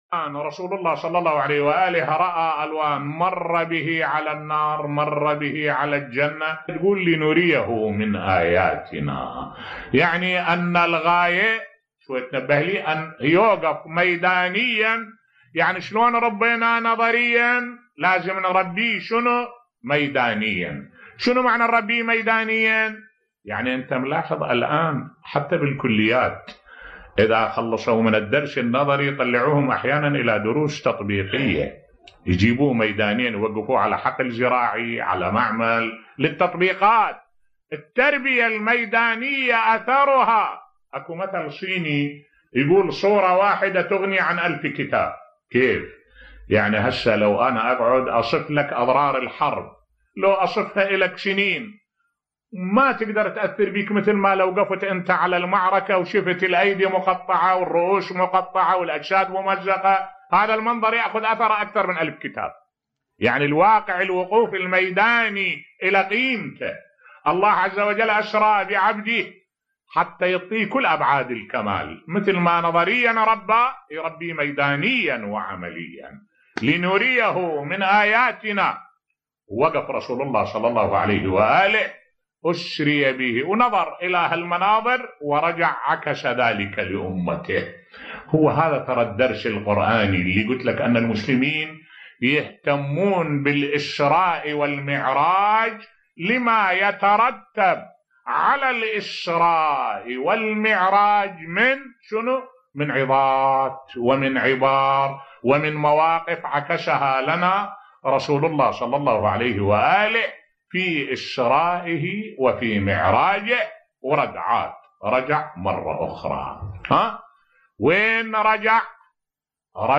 ملف صوتی الهدف من الاسراء و المعراج برسول الله بصوت الشيخ الدكتور أحمد الوائلي